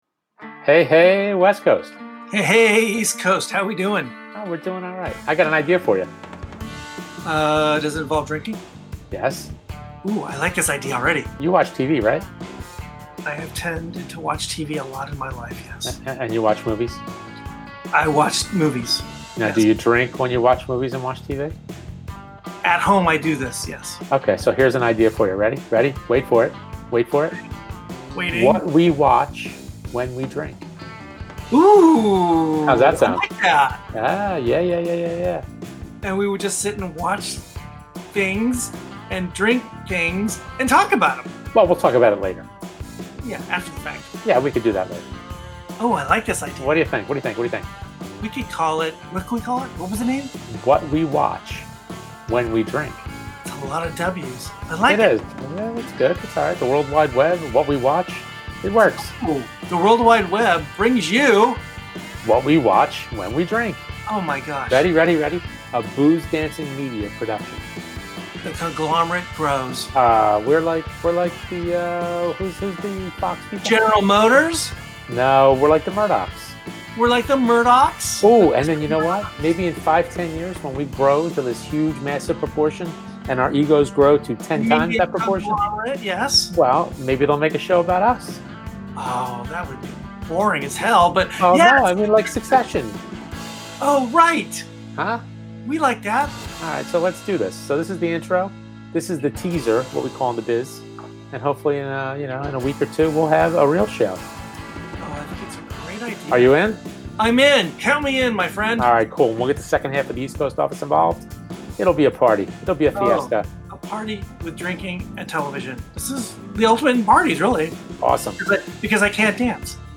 Welcome to What We Watch When We Drink, a BoozeDancing Media podcast featuring lively discussions about movies, TV shows, and anything else that we’re watching while we drink our favorite boozy libations.